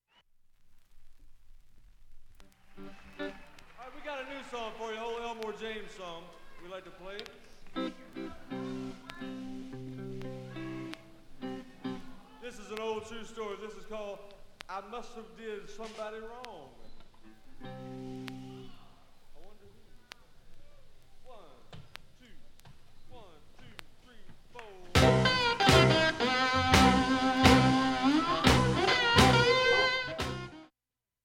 盤面きれいでクリアーな音質良好全曲試聴済み。
A-2始め喋りの部で２８秒の間に
12回の周回プツ出ますがかすかです。
1971年ロック史上屈指の歴史的傑作ライヴ・アルバム